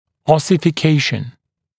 [ˌɔsɪfɪ’keɪʃn][ˌосифи’кейшн]оссификация, окостенение, образование костного вещества